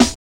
HISSER.wav